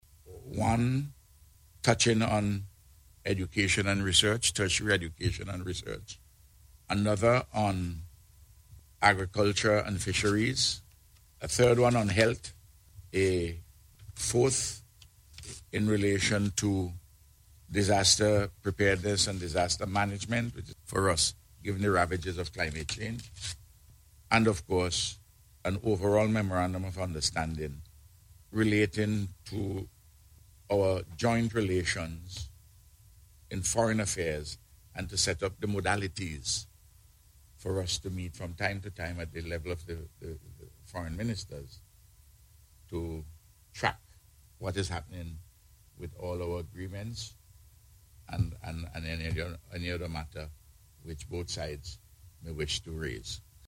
Prime Minister Dr. Ralph Gonsalves made the announcement during a Media Conference which was held this morning to provide an update on his visit to Romania.